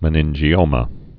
(mə-nĭnjē-ōmə)